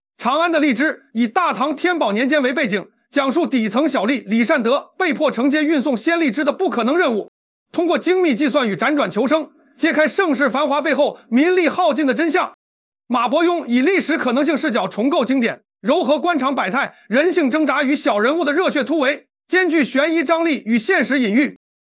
这段让人难辨真假的音频并非真正出自李善德之口，其应用的正是科大讯飞近期升级的声音复刻技术，李善德痛斥右相的段落中，声调拔高，语速加快，声音复刻技术呈现的李善德推荐《长安的荔枝》音频中，不仅将声线高度契合，而且把语音中包含的情绪也复刻得非常到位。